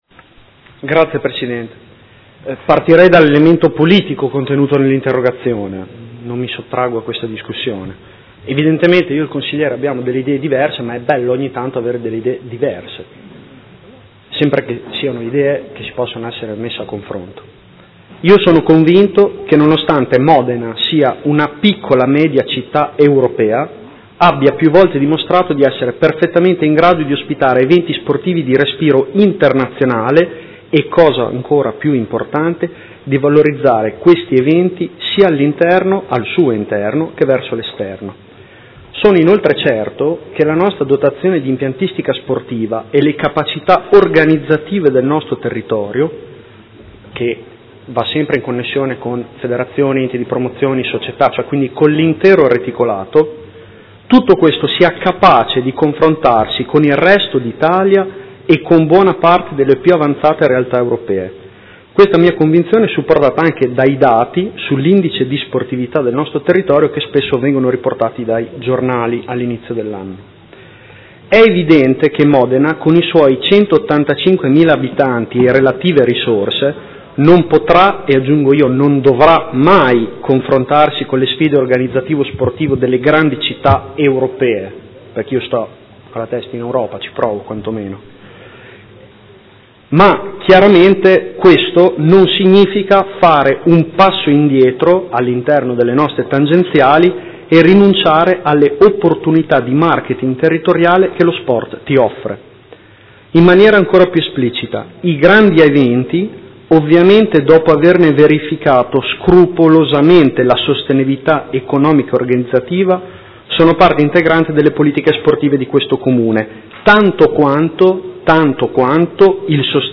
Seduta del 18/02/2016 Risponde. Interrogazione del Gruppo Consiliare Movimento 5 Stelle avente per oggetto: La Grande Sfida